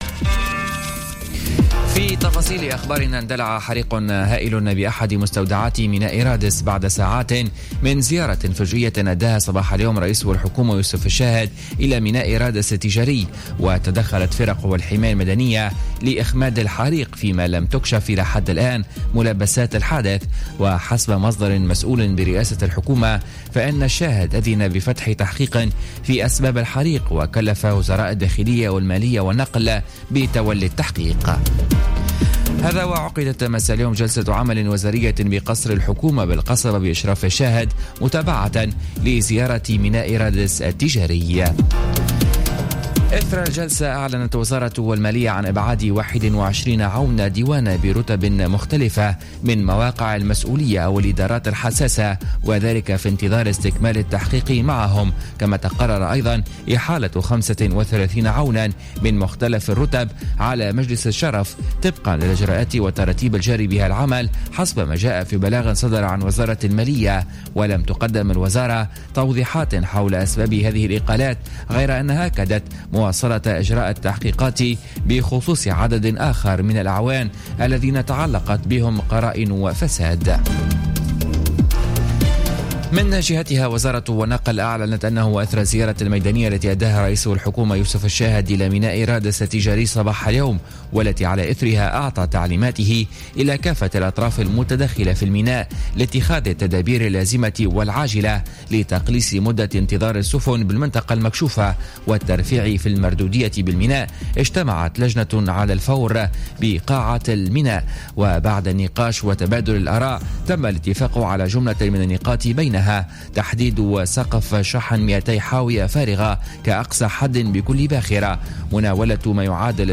نشرة أخبار السادسة مساء ليوم الاربعاء 14 جوان 2017